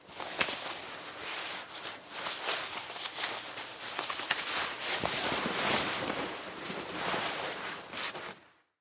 Звуки пальто
Звук: укутываем тело в пальто